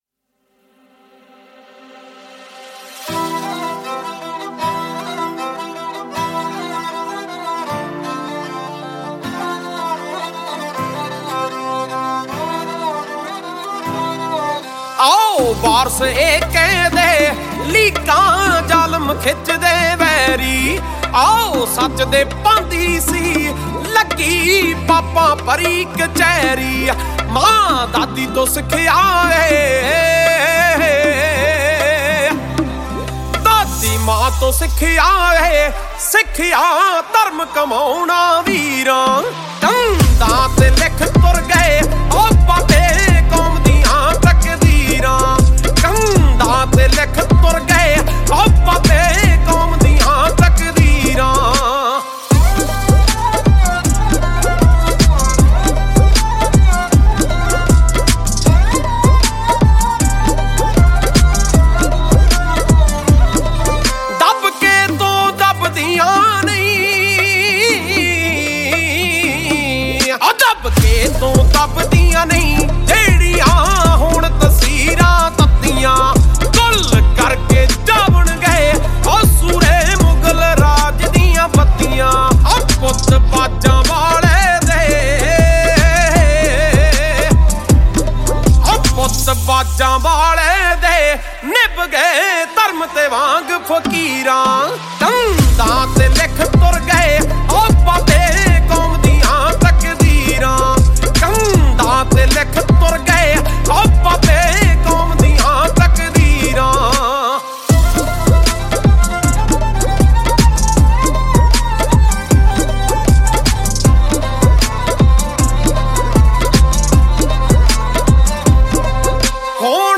Song Genre : Punjabi Songs